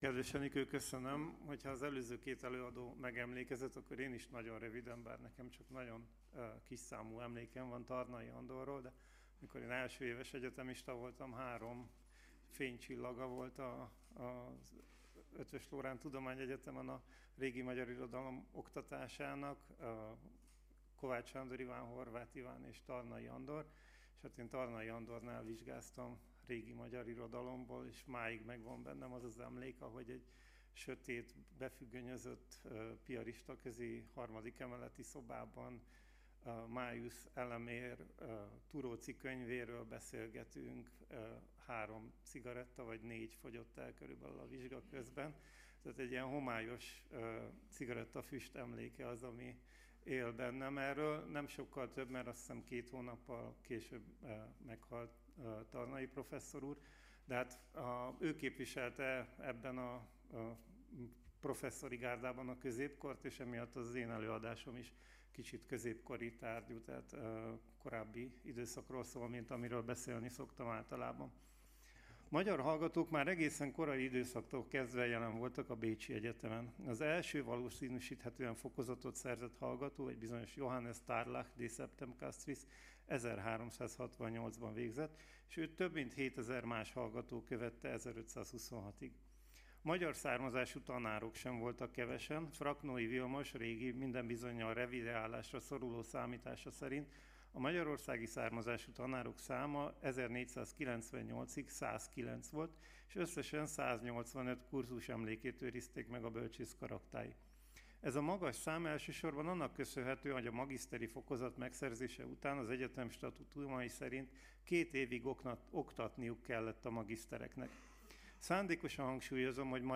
Száz éve született Tarnai Andor , Negyedik ülés